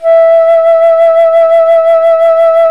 FLT FLUTE 27.wav